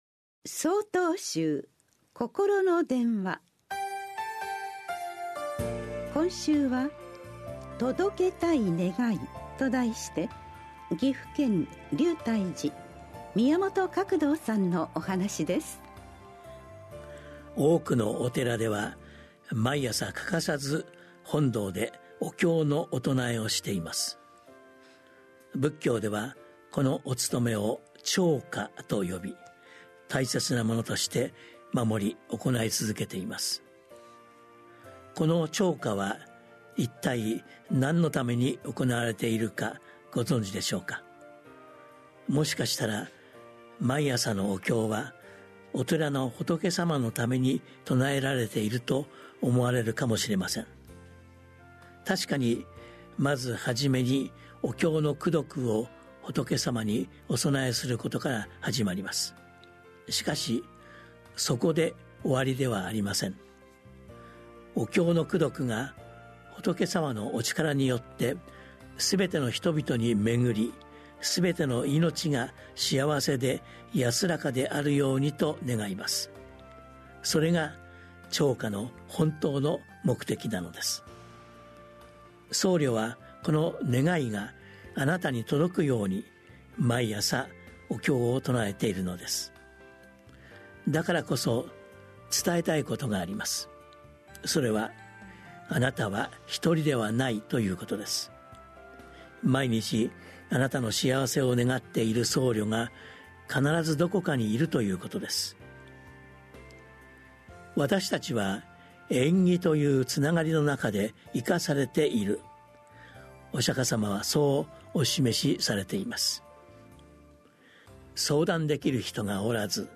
曹洞宗がお届けするポッドキャスト配信法話。 禅の教えを踏まえた「ほとけの心」に関するお話です。